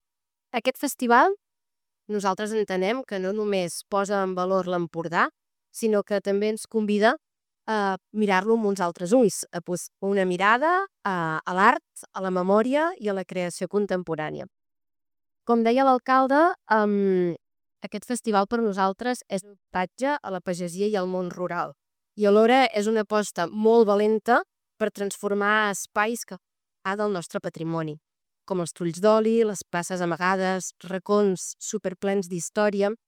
Són declaracions de la diputada d’Educació i Joventut de la Diputació de Girona, Mònica Alcalà.